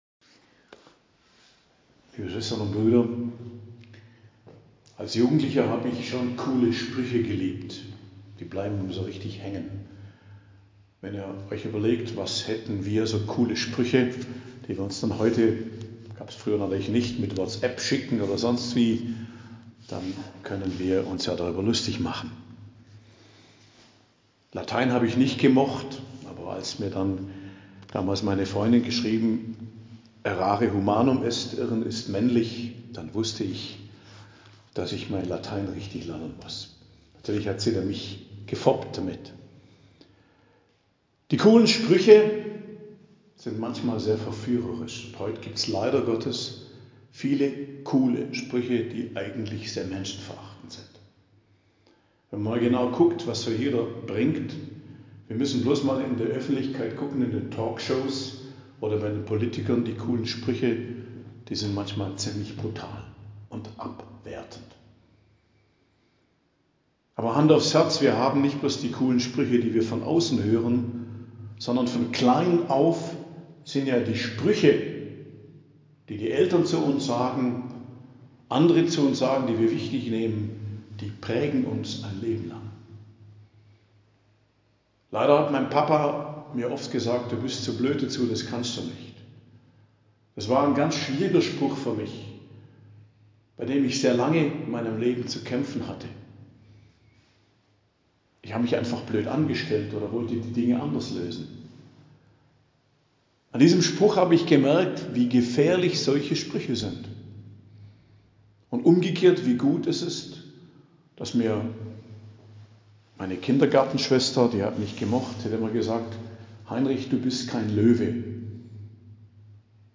Predigt am Donnerstag der 3. Woche der Fastenzeit, 7.03.2024